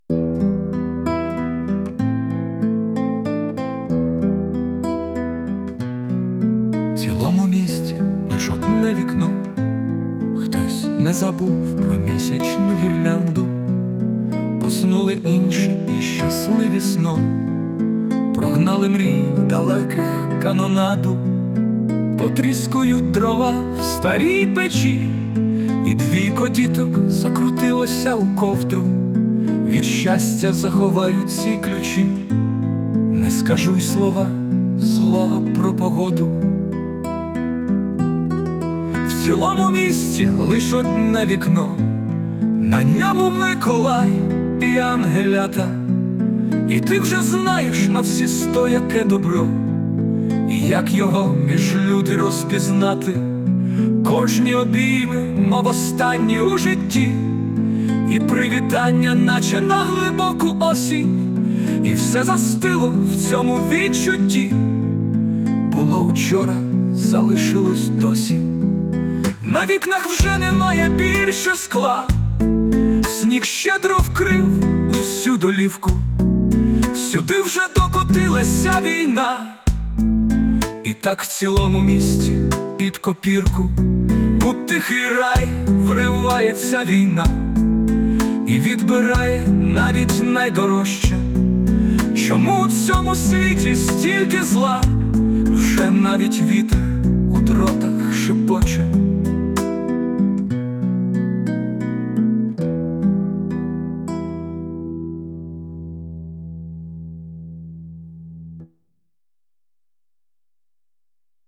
Музичне прочитання з допомогою ШІ
СТИЛЬОВІ ЖАНРИ: Ліричний
Рядочки проходять крізь серце, а музикальний супровід ще більше підсилює ці відчуття! flo26 flo31 flo36